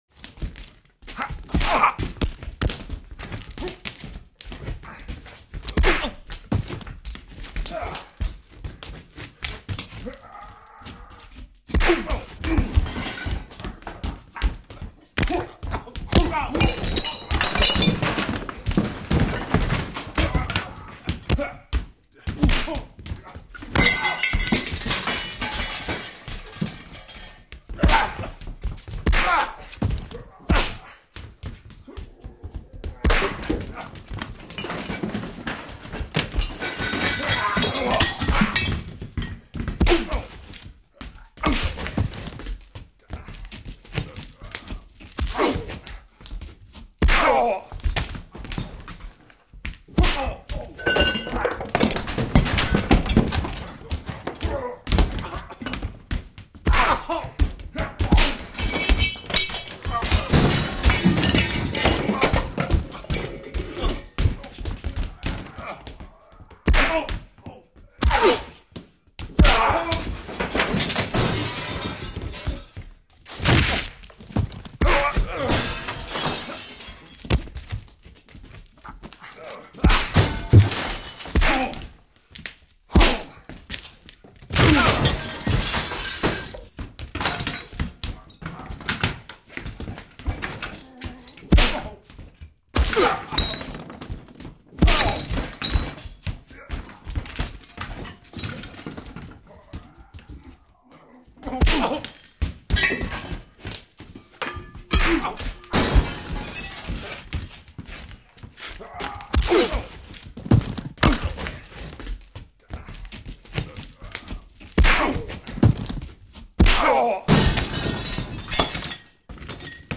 Драка в квартире